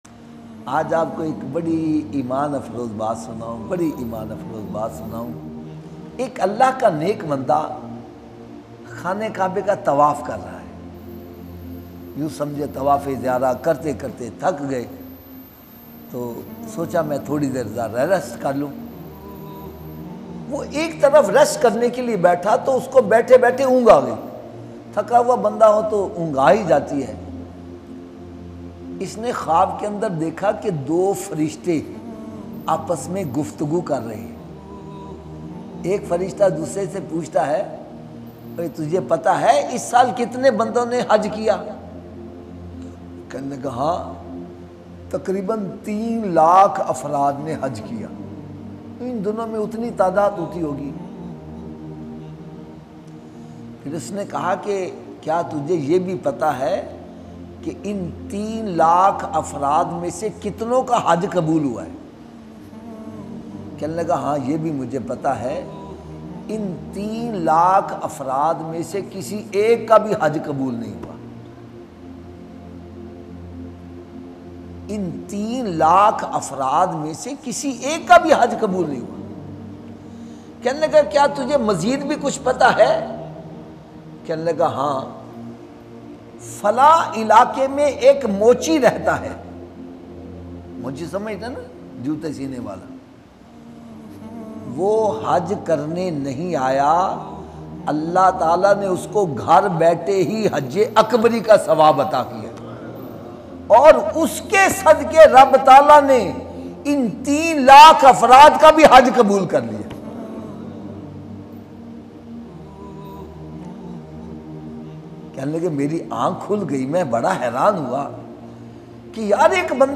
Hajj Bayan - Ghar Baithe Hajj e Akbar Ka Sawab